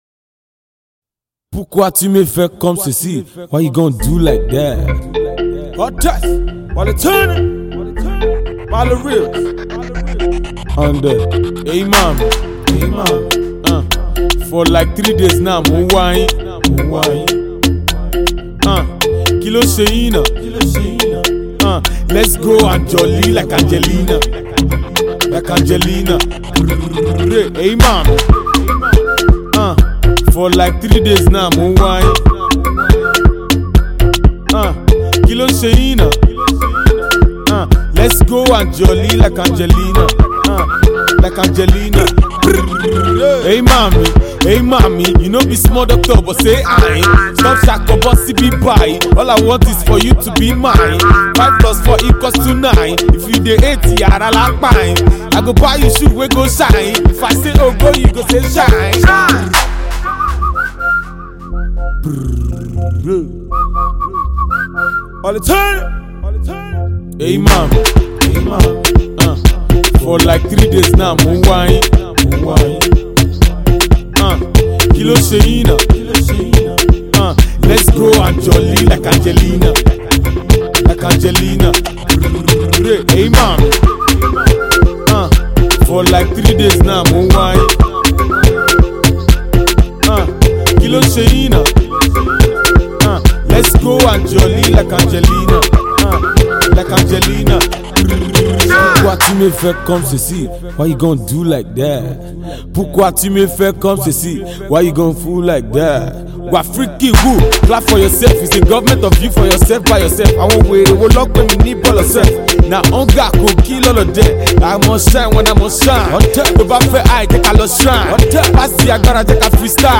Nigerian Versatile Yoruba Rapper